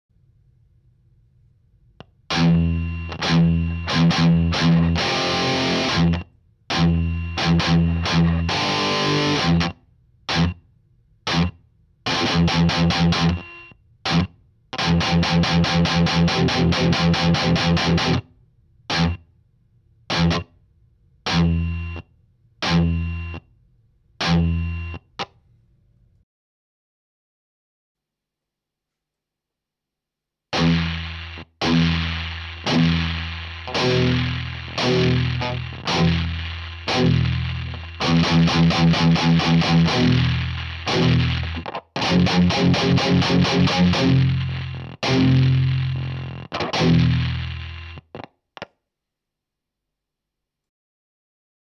Nun zum Problem: Trotz korrekt eingestelltem Input Trim und ausgeschalteten IRs (Global Cabinet setting: Bypass) höre ich ein heftiges Rauschen beim spielen. Dieses wird nach dem Spielen relativ schnell durch das, im ToneX integrierten, Noise Gate wieder unterdrückt und es ist vorübergehend ruhig. Sobald ich jedoch wieder anfange zu spielen und das Gate öffnet ist das rauschen selbst beim Spielen im Hintergrund deutlich zu hören (besonders schlimm bei Palm-mutes).
UPDATE: Beispiel zu finden im Anhang (Zuerst meine Harley Benton bei der das Problem nicht auftritt, danach meine Ibanez mit dem oben beschriebenen Rauschen) Anhänge ToneX_Rauschproblem mit Ibanez_mp3.mp3 808,6 KB